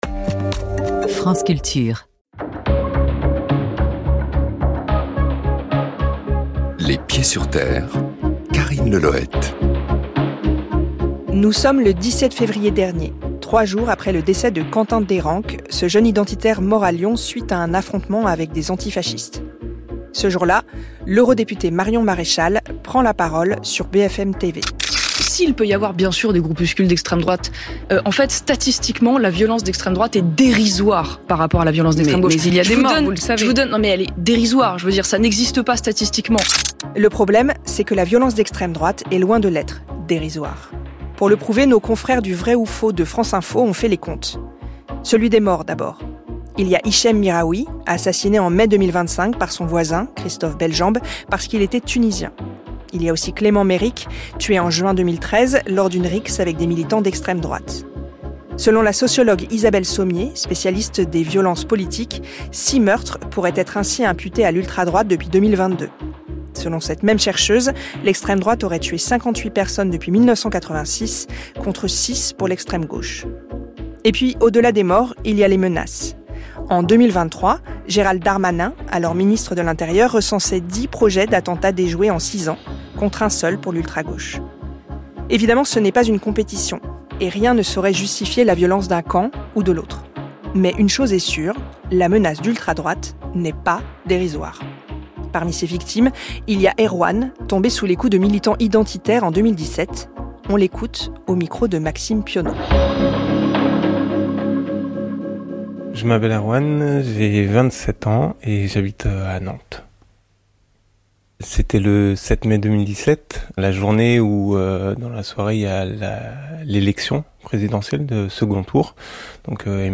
Dans cette masterclasse, Souleymane Bachir Diagne, voix majeure de la philosophie contemporaine, revient sur une œuvre plurielle, habitée par la question du décentrement et de la traduction, et marquée par le souci constant de faire converger les traditions philosophiques.